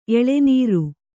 ♪ eḷenīru